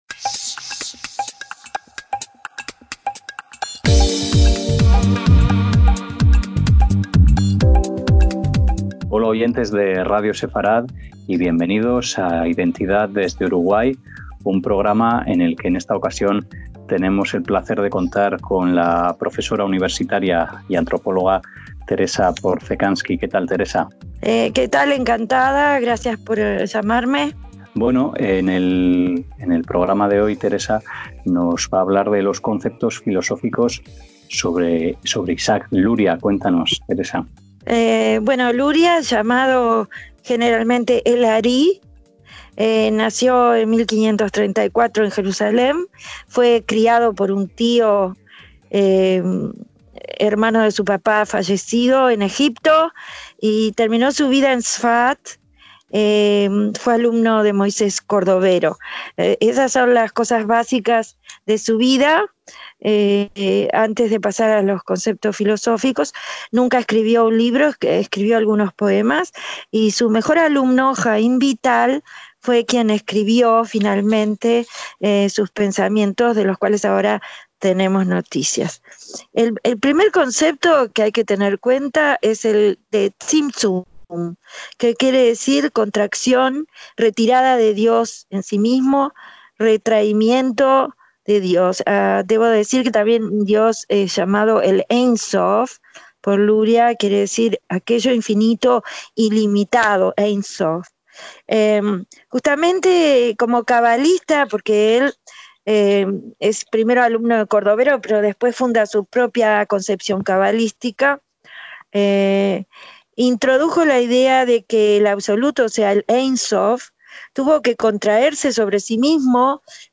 IDENTIDAD, DESDE URUGUAY - La antropóloga y profesora universitaria Teresa Porcecanski nos habla sobre las revolucionarias ideas en el pensamiento filosófico de Isaac Luria.